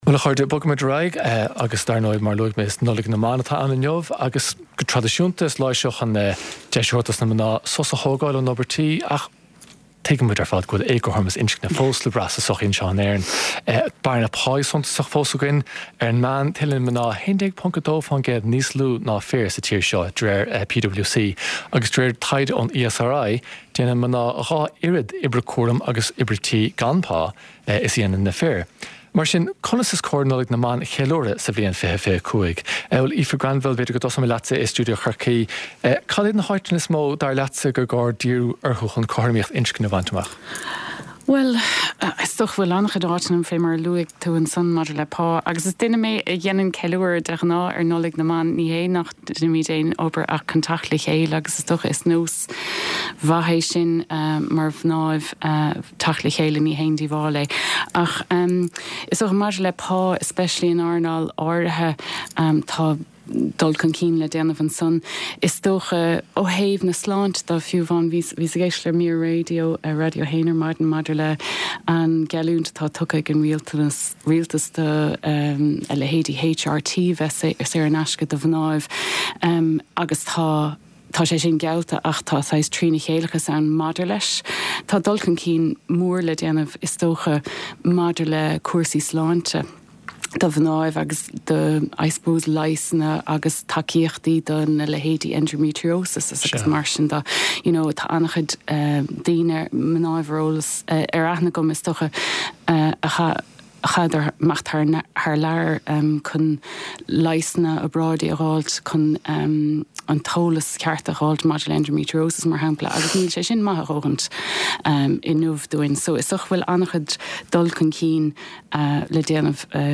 Clár cúrsaí reatha an tráthnóna á chur i láthair ón Lárionad Raidió i mBaile Átha Cliath. Scéalta náisiúnta agus idirnáisiúnta a bhíonn faoi chaibidil ar an gclár, le plé, anailís agus tuairiscí.